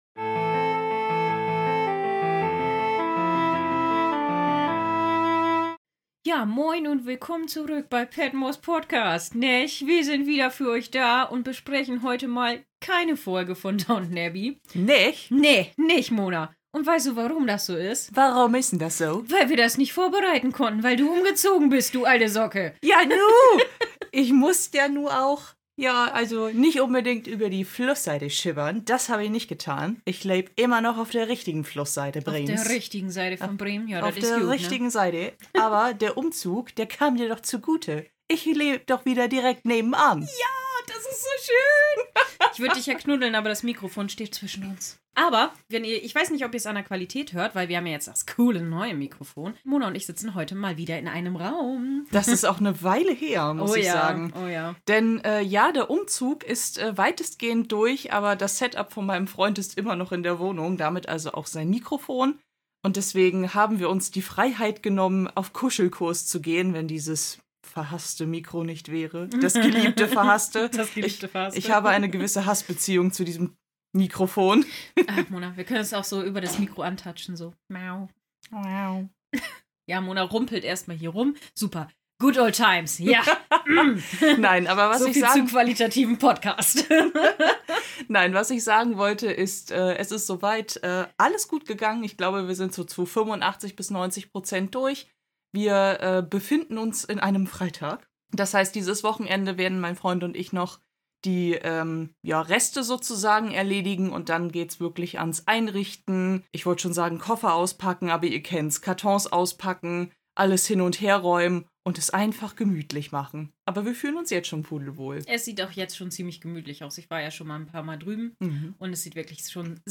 Es wird gelacht, gesungen und es gibt Anekdoten aus unserer Schulzeit.